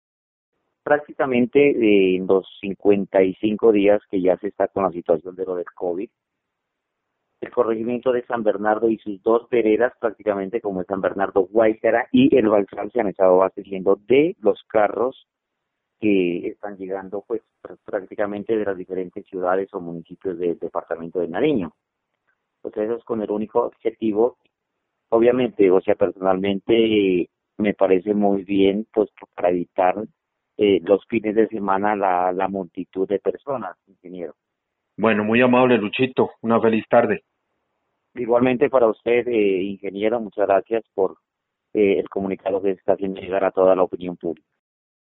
A los líderes comunitarios les hicimos la misma pregunta: ¿En la actualidad en donde se abastecen de alimentos las familias de su corregimiento?